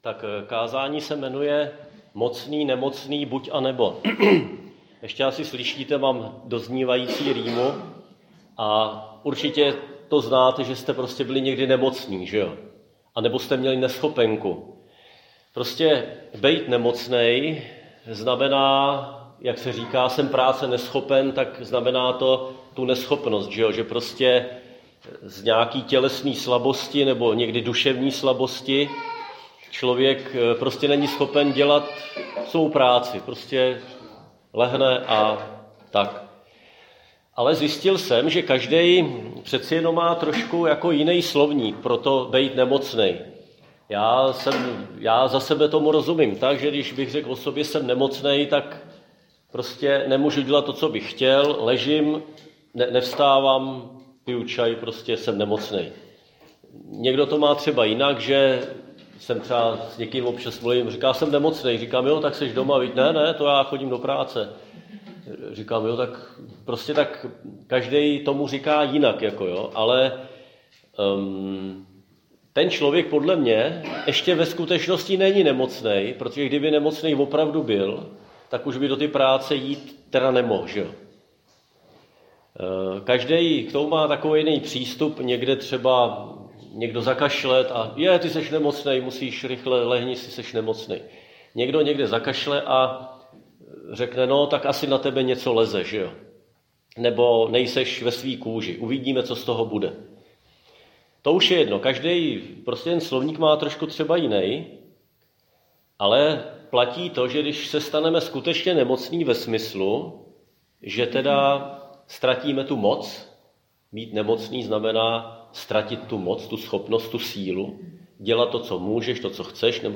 Křesťanské společenství Jičín - Kázání 26.3.2023